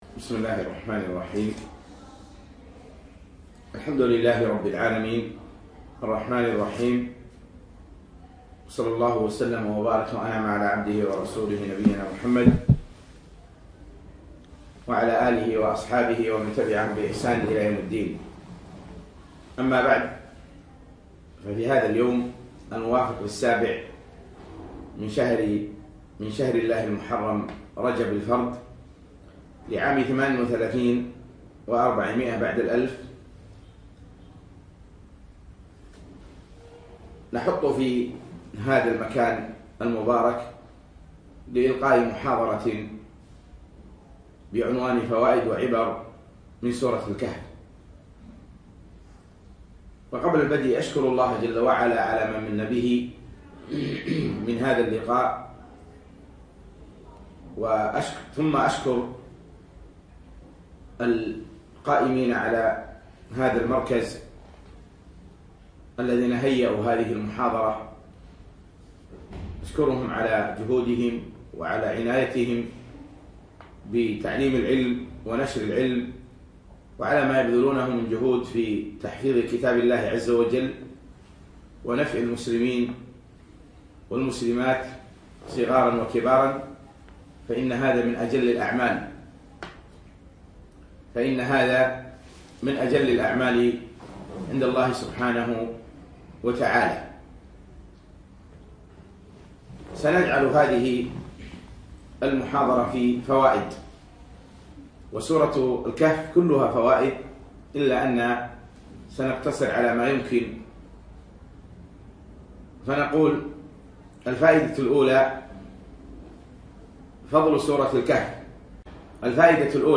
يوم الثلاثاء 7 رجب 1438 الموافق 5 4 2017 في مركز إلهام البوشي لدار القرآن نساء صباحي خيطان